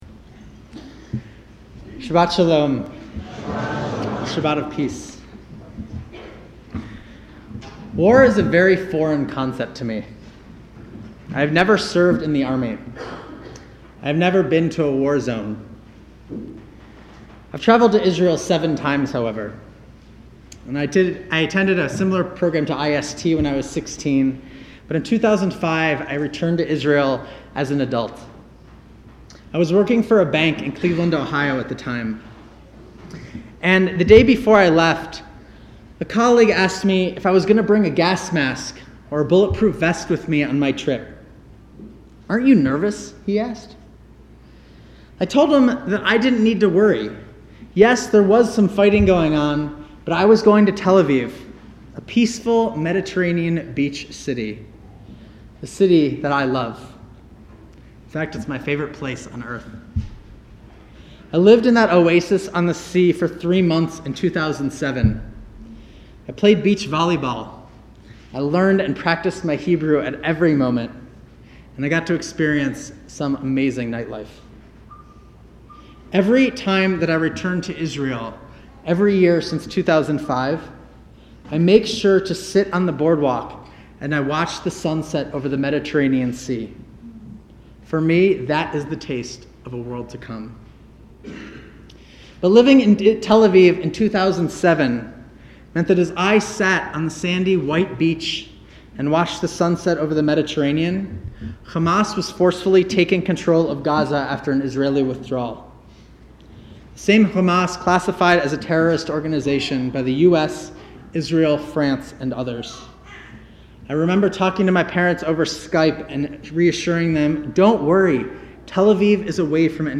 Israel Sermon